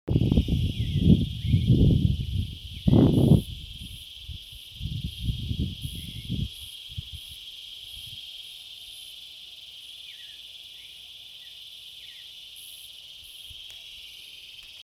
Creamy-bellied Thrush (Turdus amaurochalinus)
Province / Department: La Pampa
Location or protected area: Entre Santa Rosa y Toay
Condition: Wild
Certainty: Recorded vocal
Zorzal-Chalchalero.mp3